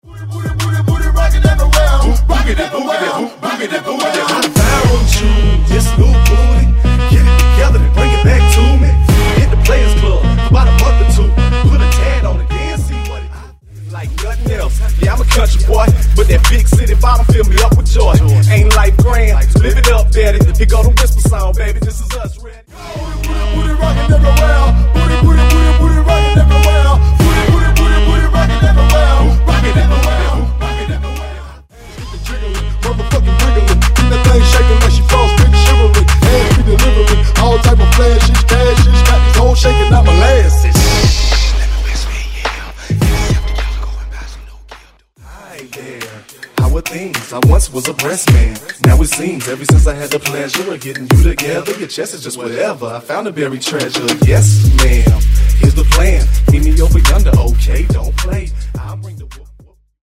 Genres: DANCE , MASHUPS , TOP40
Clean BPM: 125 Time